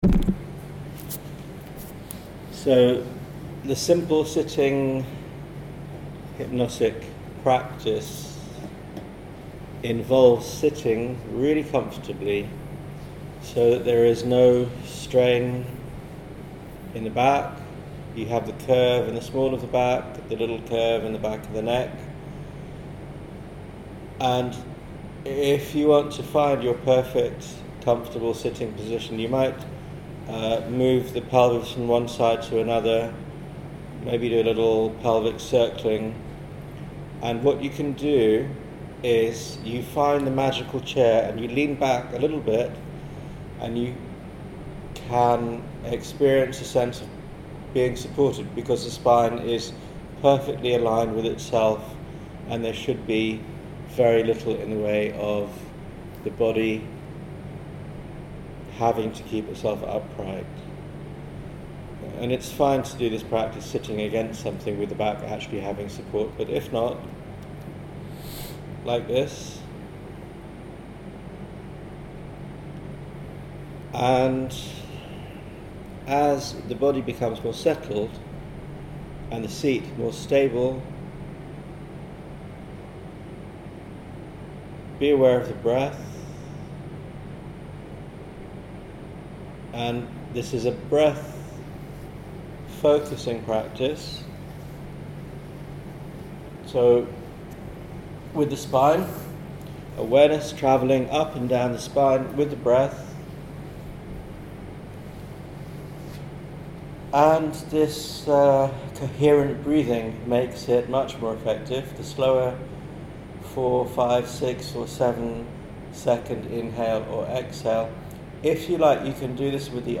Vocal Mode: Spoken word